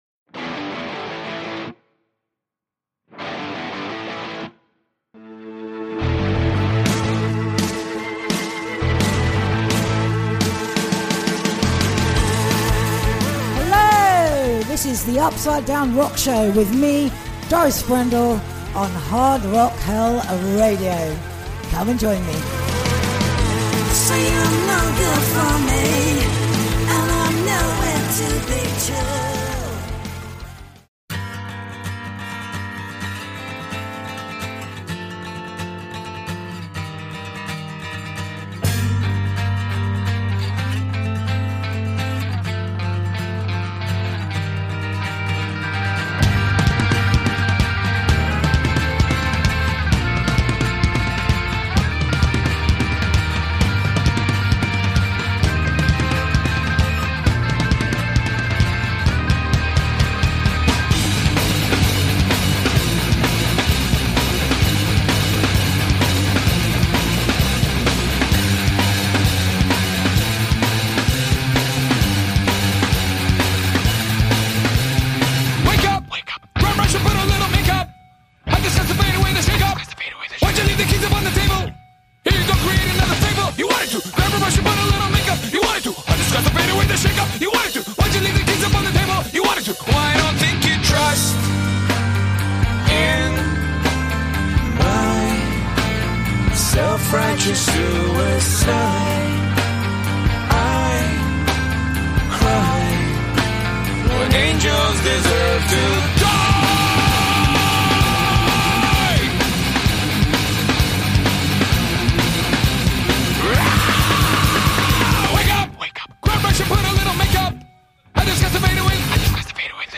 We celebrate the 100th Show with classic and favourite blasts from the past along with a few new rock releases.